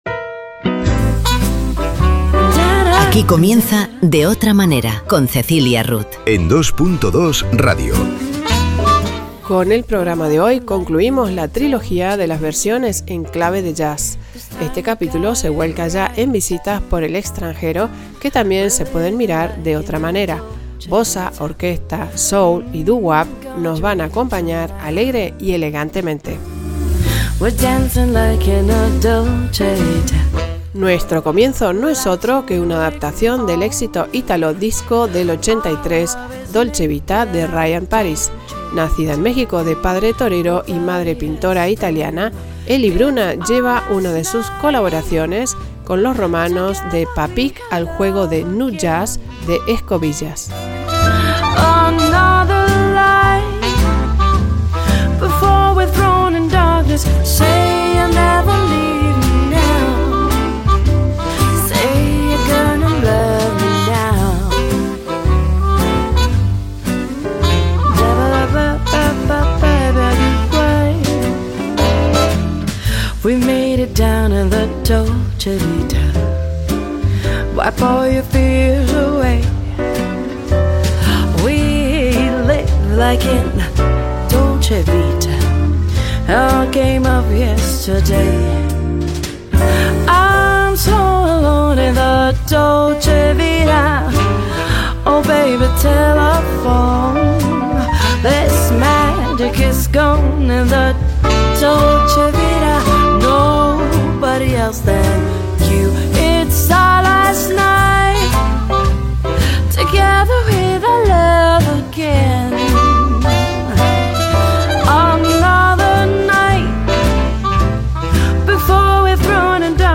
Bossa, orquesta, soul y doo wap nos van a acompañar alegre y elegantemente.